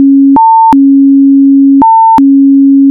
FSK45.wav